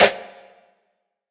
SNARE - BESTY.wav